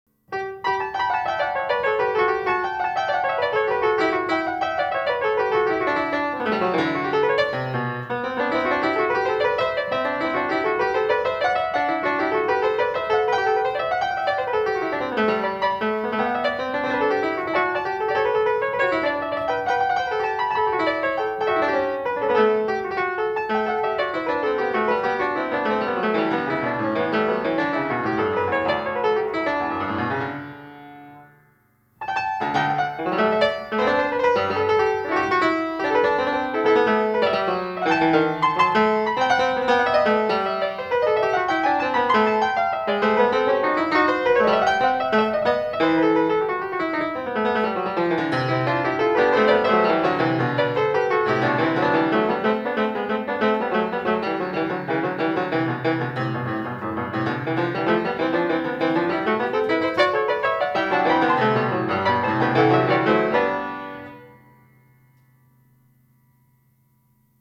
I've been playing the piano since I was 9 years old.
You can judge my skills for yourself through this excerpt of a home recording I made of Bach's Goldberg Variations. Please don't mind the sound of the piano itself because it's a 100-year old grandmother.